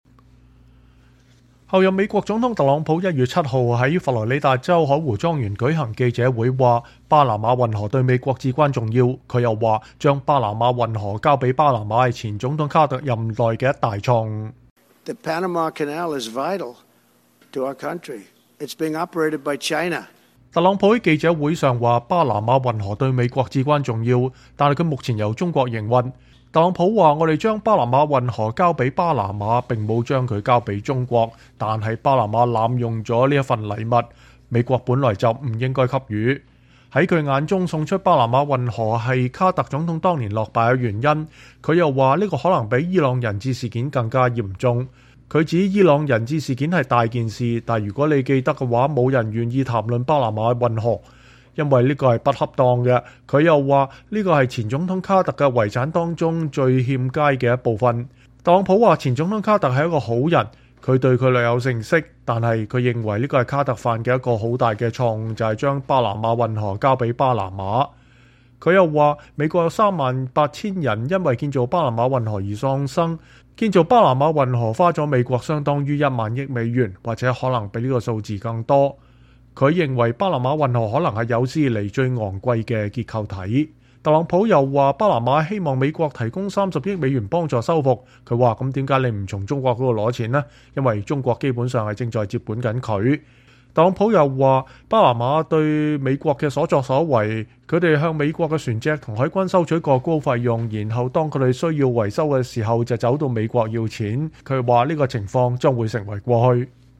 美國候任總統特朗普1月7日在佛羅里達州海湖莊園舉行的記者會中說，“巴拿馬運河對美國至關重要”。他又說，把巴拿馬運河交給巴拿馬是前總統卡特任內的一大錯誤。